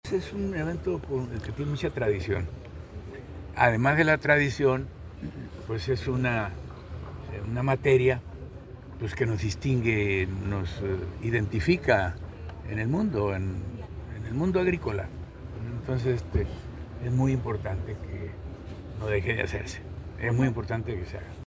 En entrevista, el mandatario estatal precisó que esta tradicional exposición es organizada por la CAADES y la Fundación Produce, y el Gobierno del Estado apoya al comité organizador para la realización del evento, que los últimos años ha mantenido como sede el Campo Experimental de la Fundación Produce, en la sindicatura de Aguaruto.